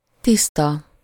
Ääntäminen
Ääntäminen Tuntematon aksentti: IPA: /ˈtistɒ/ Haettu sana löytyi näillä lähdekielillä: unkari Käännös Ääninäyte Adjektiivit 1. clean US UK 2. clear US GenAm 3. pure US 4. immaculate Luokat Adjektiivit